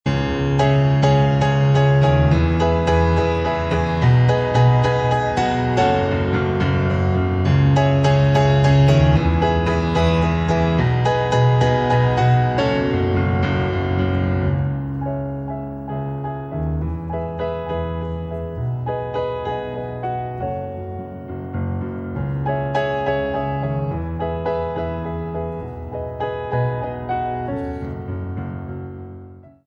7. Changer de nuance :